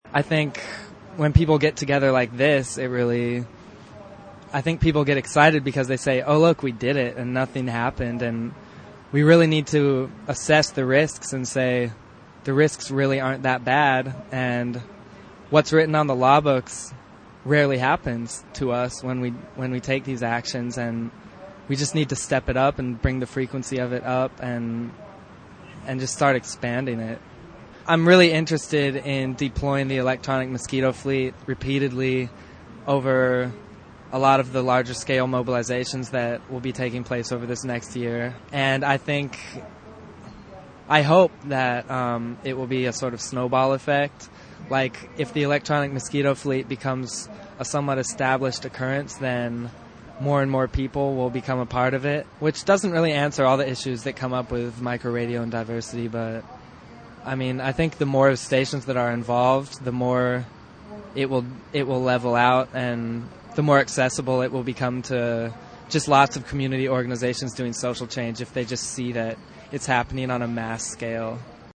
We'll begin with cuts from interviews done with a handful of Mosquito Fleet participants; it was hard to catch people flitting back and forth between transmitters, and some people don't want any publicity.